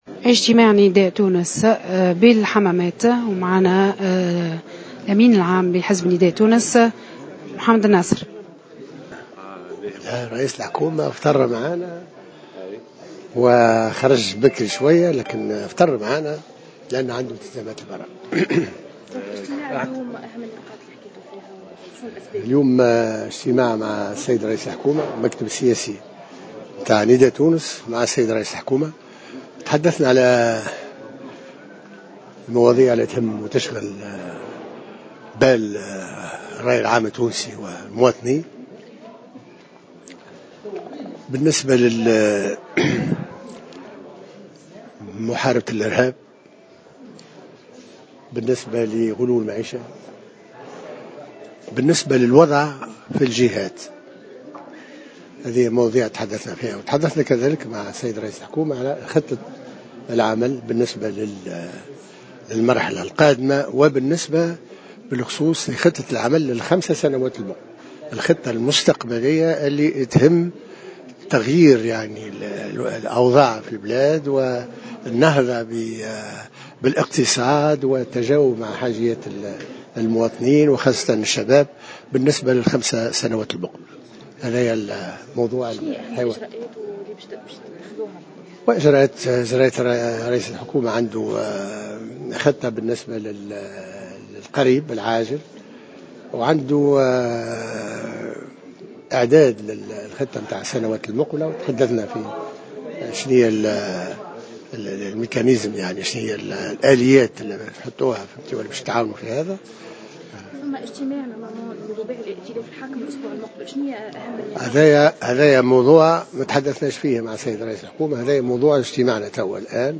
وقال محمد الناصر، رئيس مجلس نواب الشعب في تصريح خص به إذاعة الجوهرة إن الاجتماع تناول سبل مقاومة الإرهاب وغلاء المعيشة وتم تدارس الوضع في الجهات.